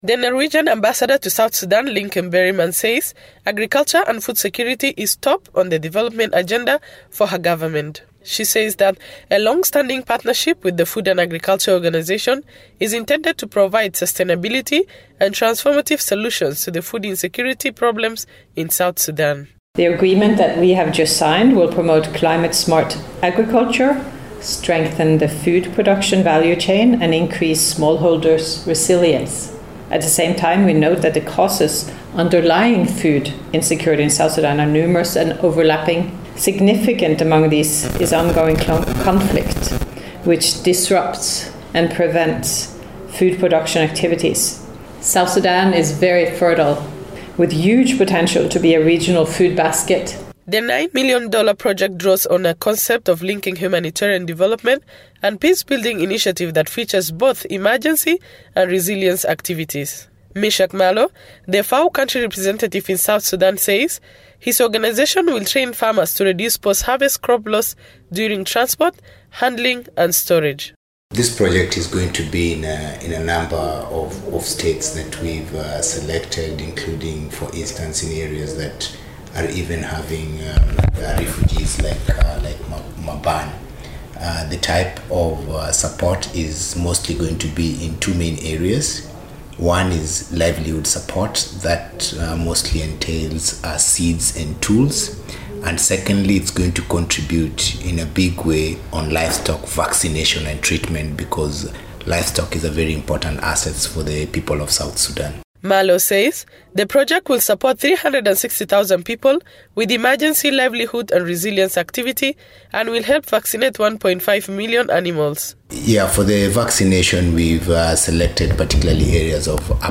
reports from Juba